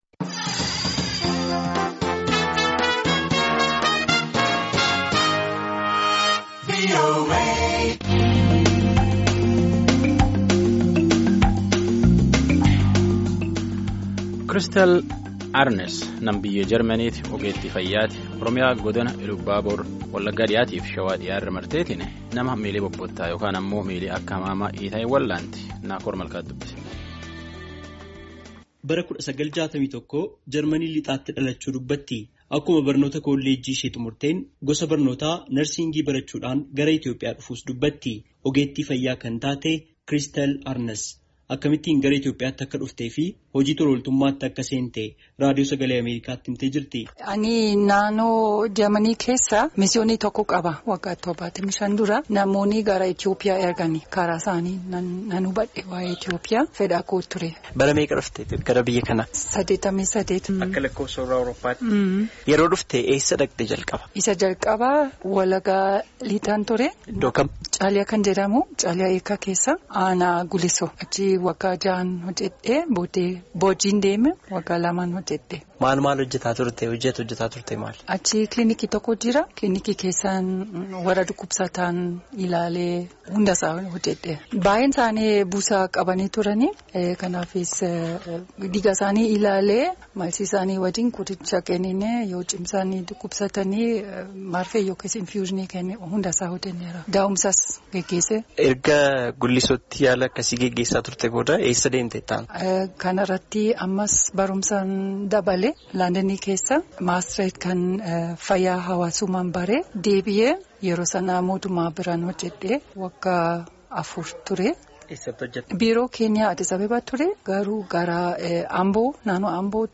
Gaaffii fi deebii Afaan Oromootiin waliin geggeessine :